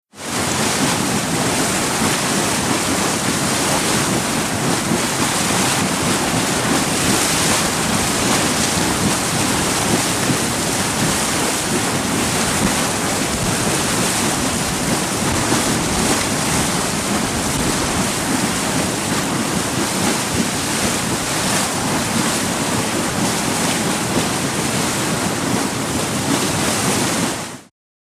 WATER NATURE HOT SPRING: Boiling and roaring. Yellowstone, Dragon`s Mouth.